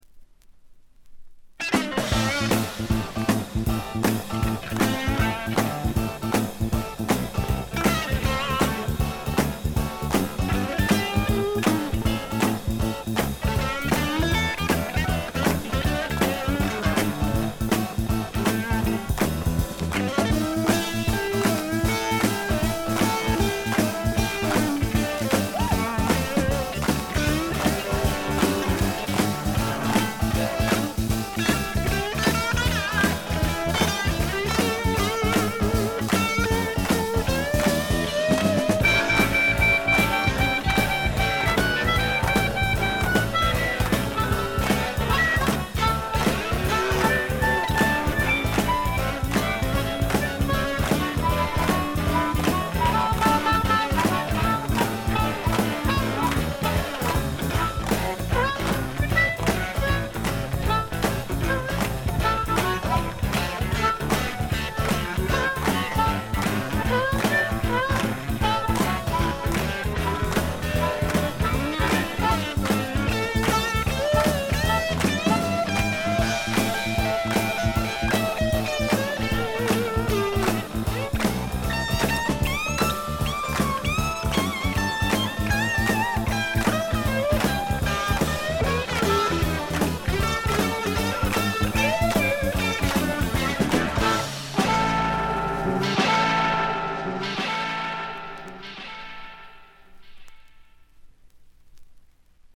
ほとんどノイズ感なし。
よりファンキーに、よりダーティーにきめていて文句無し！
試聴曲は現品からの取り込み音源です。